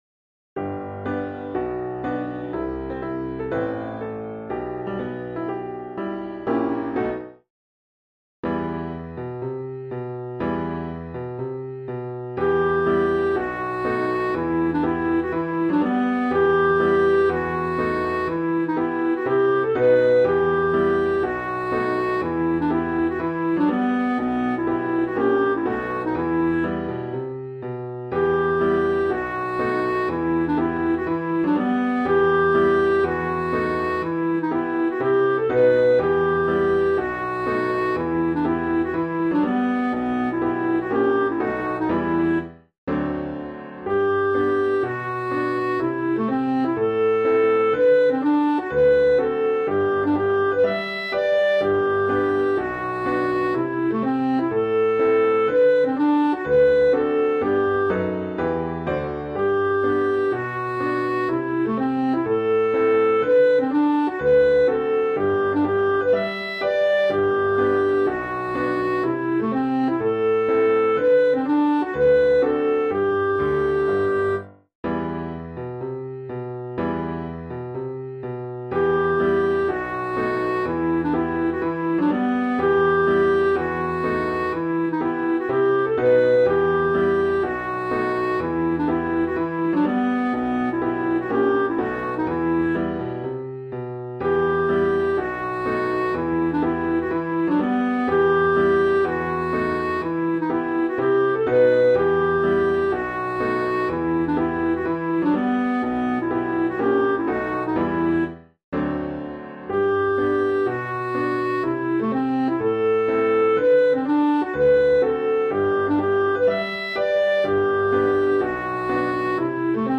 music hall song
piano/vocal